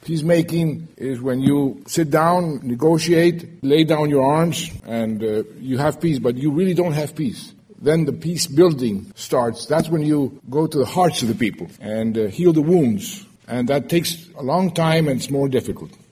His lecture, named “From Hawk to Dove,” was delivered in front of a packed Forum Hall in the K-State Student Union and reflected on Santos’s experiences with war and peace and, at times, waging war to bring about peace.